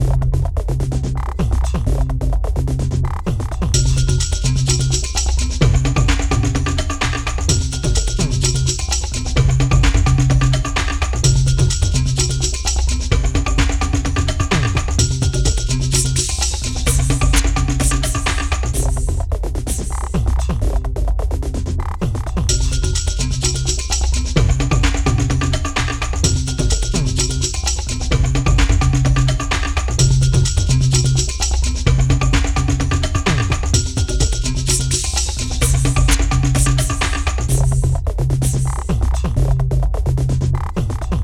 Tecno étnico 1 (bucle)
tecno
melodía
repetitivo
ritmo
sintetizador